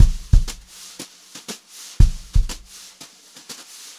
120JZBEAT6-L.wav